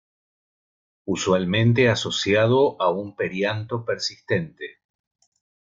Read more persistent Frequency C2 Hyphenated as per‧sis‧ten‧te Pronounced as (IPA) /peɾsisˈtente/ Etymology Borrowed from Latin persistens In summary Borrowed from Latin persistentem.